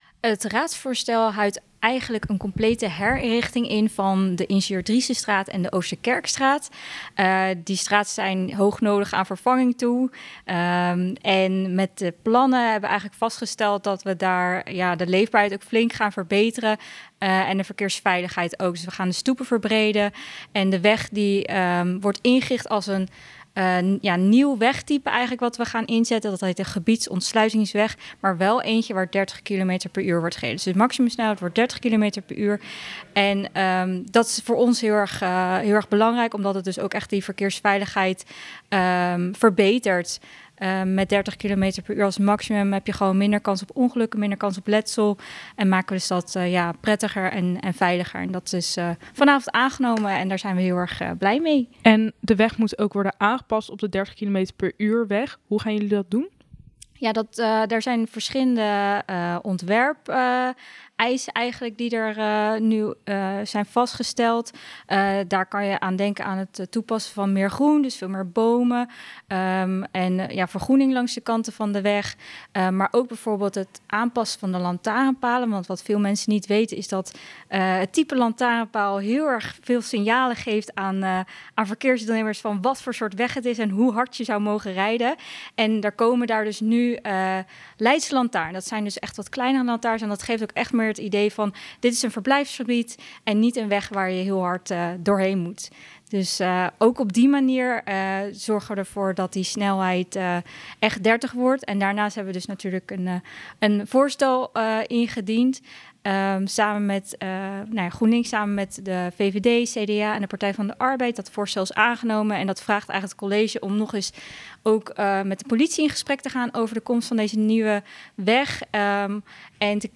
gaat in gesprek met raadslid Marleen Schreuder over de plannen, de uitvoering, de handhaving en de mogelijke terugkeer van de bushalte.